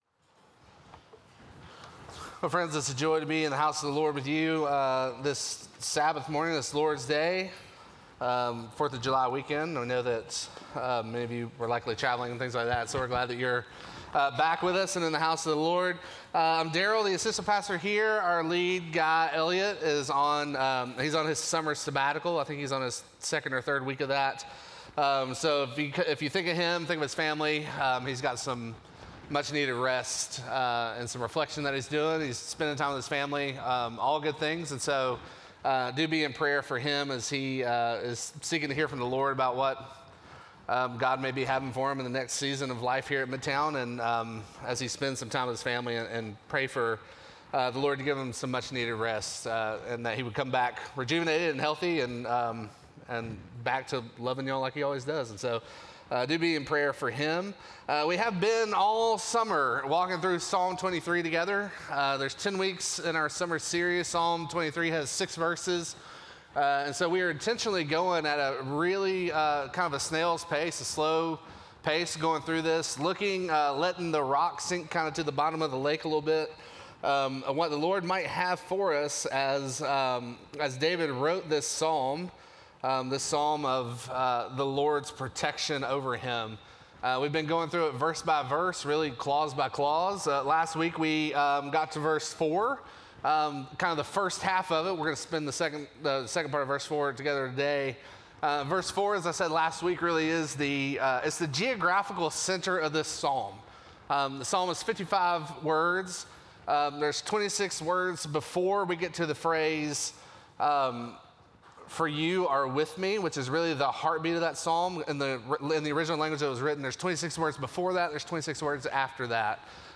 Midtown Fellowship 12 South Sermons The Rod and The Staff Jul 07 2024 | 00:36:09 1x 00:00 / 00:36:09 Subscribe Share Apple Podcasts Spotify Overcast RSS Feed Share Link Embed